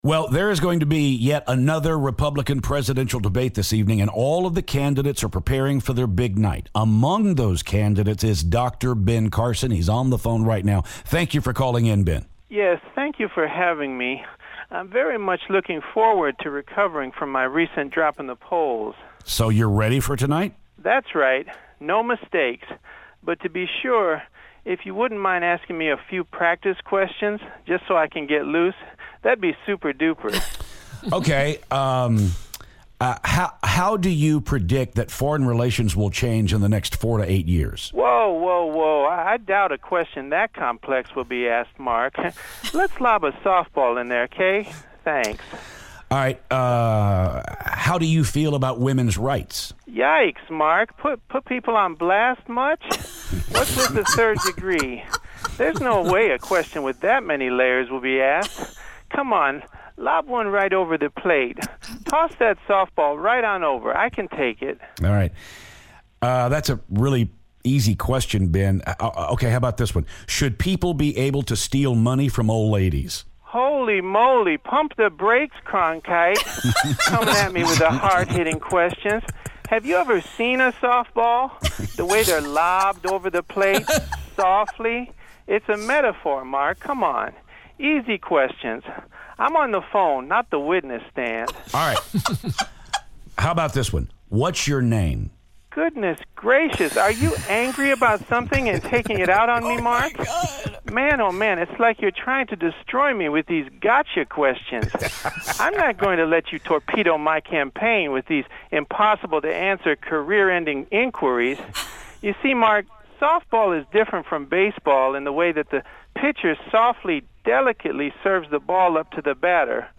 Presidential candidate Ben Carson calls to talk about the debate!